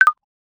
menu-exit-click.ogg